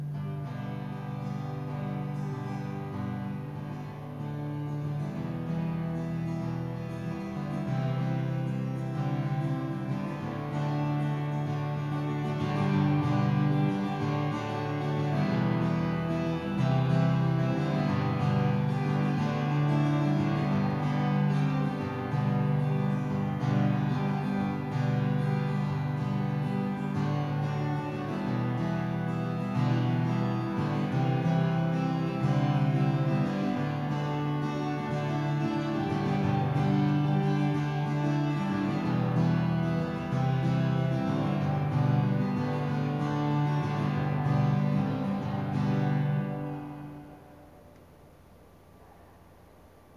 acoustic duo
(soundcheck)